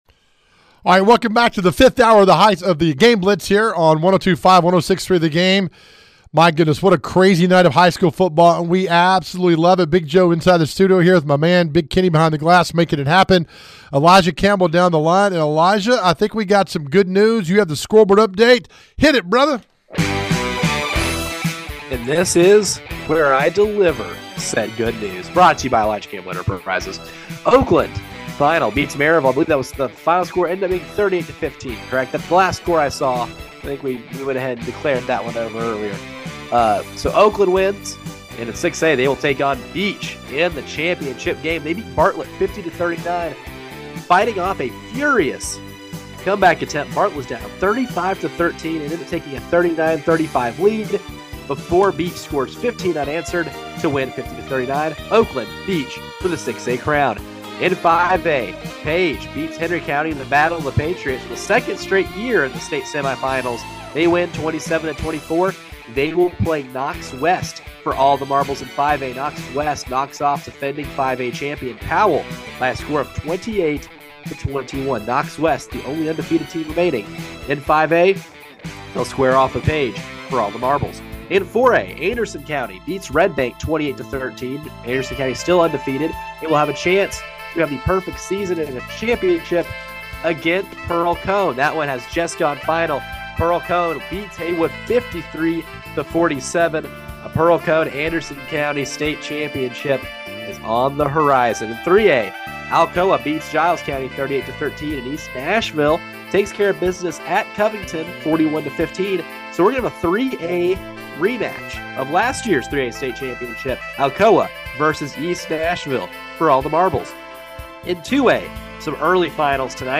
We are in hour 5 of our season finale covering a great season of Middle TN High School Football! The playoffs are in full swing, and the guys have full coverage right here with reporters on location and conversations with team coaches!